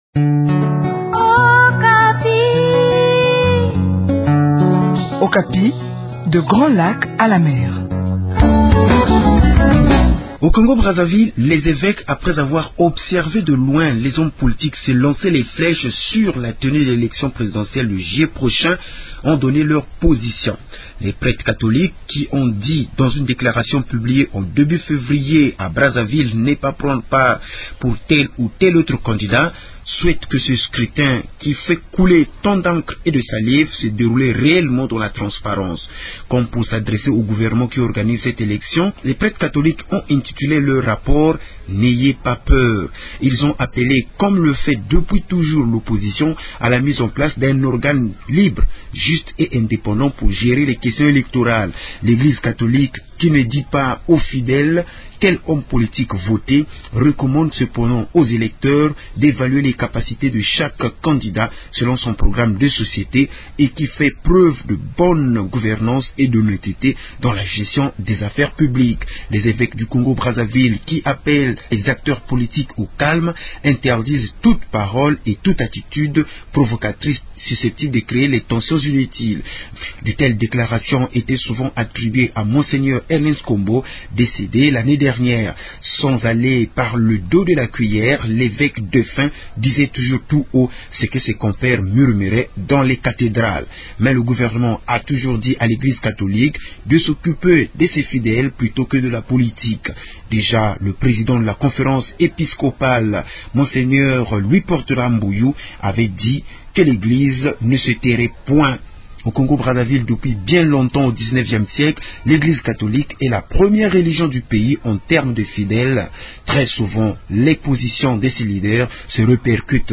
depuis Brazzaville